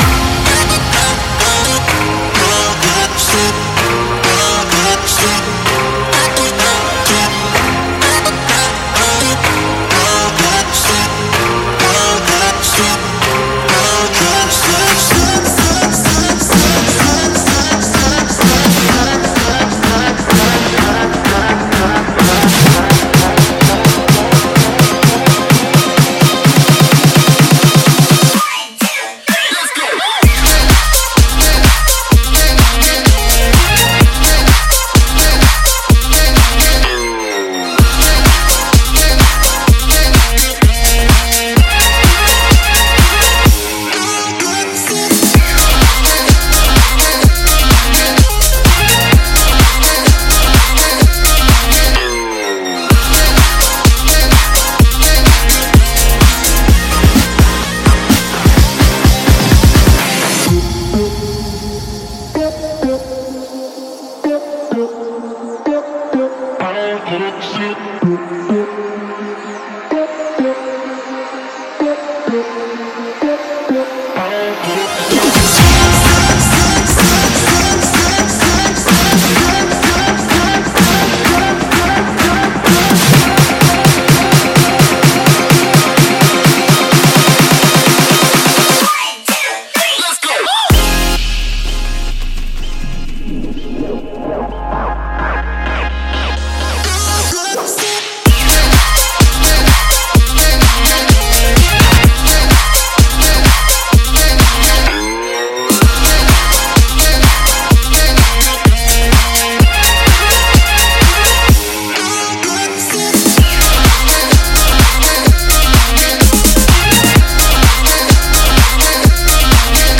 BPM64-128